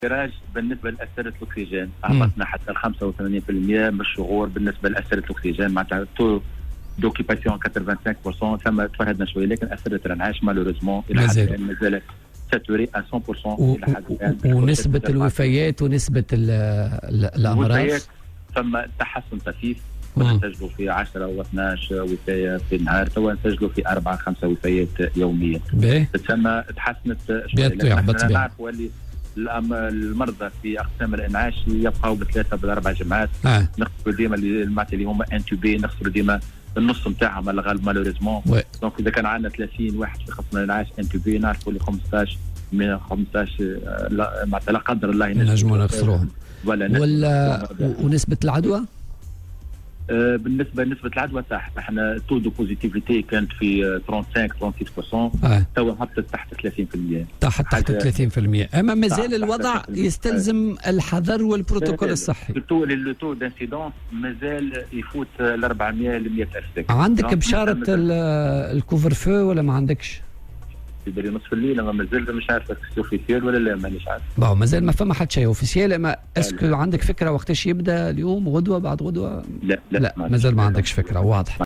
أكد المدير الجهوي للصحة بسوسة، محمد الغضباني، تسجيل تحسن في الوضع الوبائي بالجهة.
وأشار في مداخلة له اليوم على "الجوهرة أف أم" إلى وجود انفراج على مستوى أسرة الاكسيجين (تراجع نسبة الإشغال إلى أقل من 85 بالمائة في حين لا تزال اسرة الإنعاش ممتلئة).كما شهدت نسبة الوفيات تراجعا طفيفا من 10 او 12 حالة وفاة يوميا إلى 4 و5 حالات وفاة في اليوم.